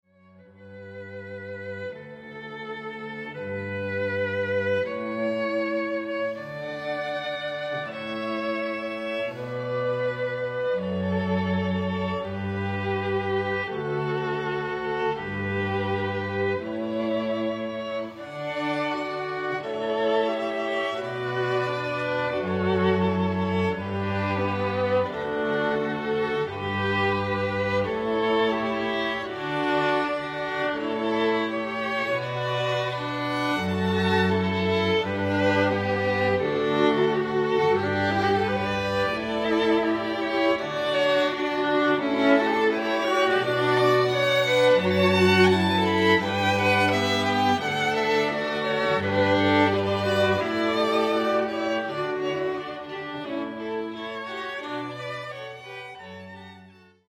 Violin
Viola
Violoncello